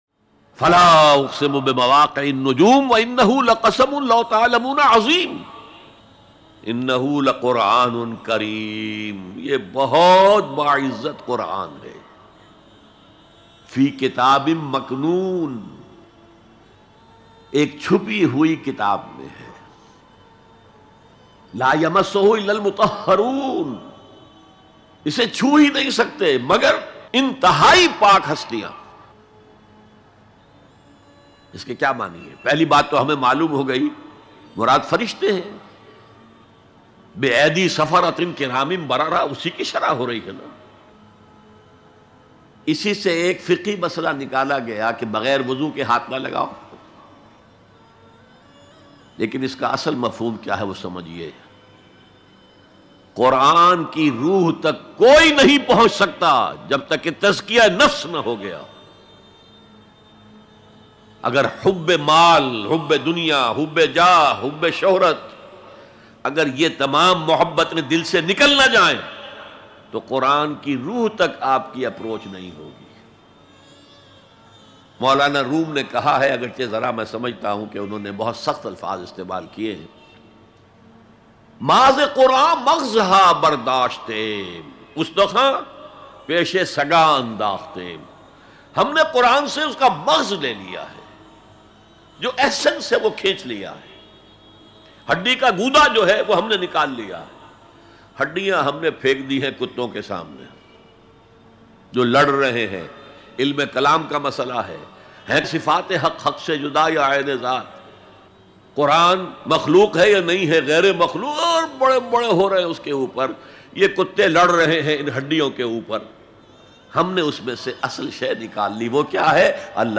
ALLAH Ka Insan Se Shikwa By Dr Israr Ahmed Bayan MP3 Download